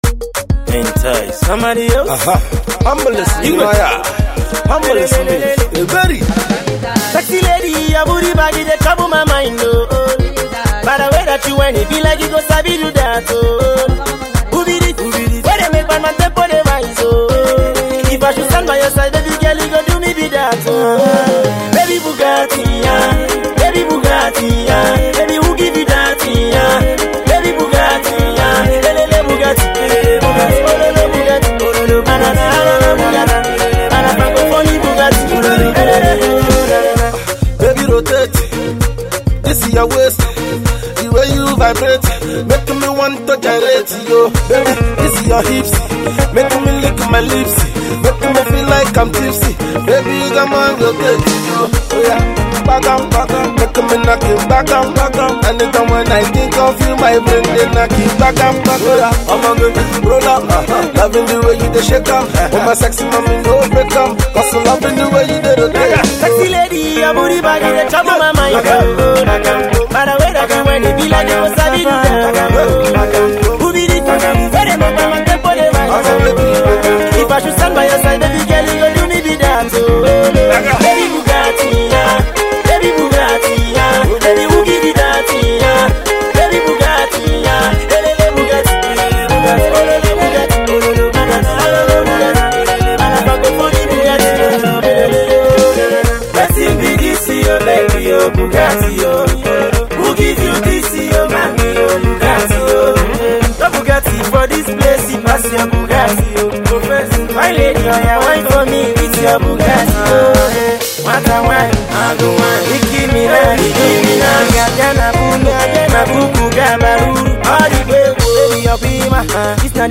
Naija Music
Nigerian Singer